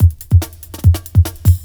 ELECTRO 06-R.wav